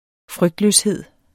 Udtale [ ˈfʁœgdløsˌheðˀ ]